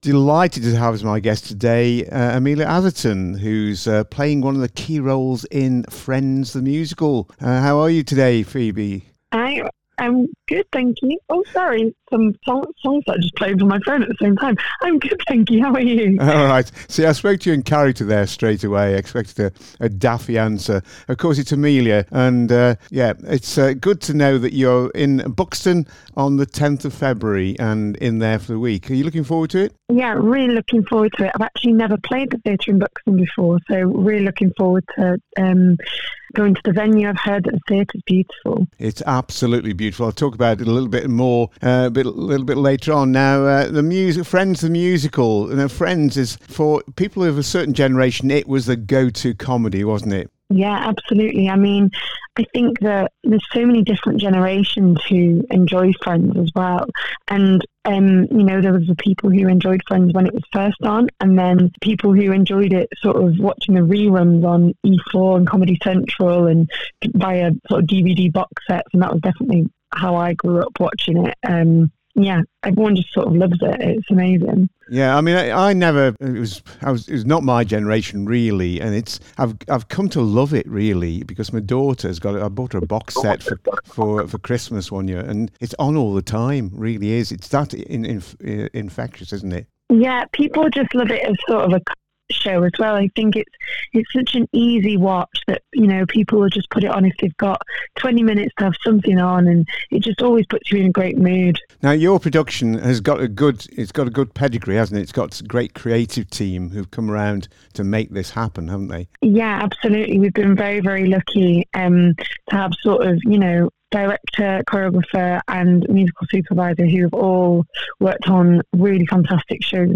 in Conversation with Friends – The One Where They Sing